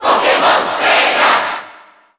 File:Pokémon Trainer Cheer Japanese SSBU.ogg
Pokémon_Trainer_Cheer_Japanese_SSBU.ogg.mp3